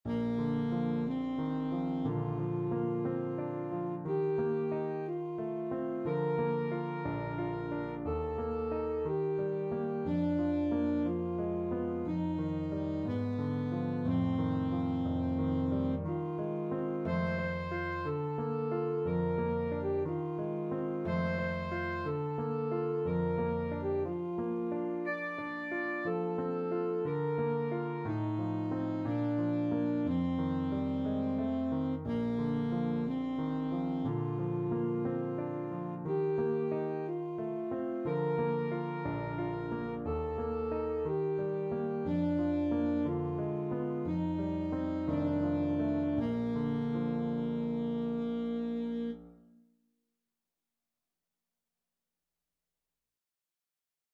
Classical Granados, Enrique Dedicatoria (from Cuentos de la Juventud, Op.1) Alto Saxophone version
Bb major (Sounding Pitch) G major (Alto Saxophone in Eb) (View more Bb major Music for Saxophone )
2/4 (View more 2/4 Music)
~ = 60 Andantino (View more music marked Andantino)
Classical (View more Classical Saxophone Music)